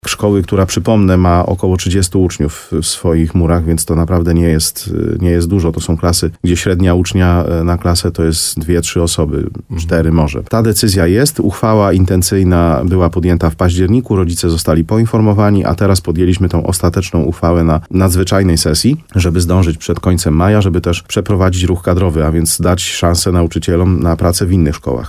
– Pismo z kuratorium w sprawie szkoły w Bieśniku otrzymaliśmy kilka dni temu – powiedział w programie Słowo za Słowo w radiu RDN Nowy Sącz wójt gminy Łużna, Mariusz Tarsa.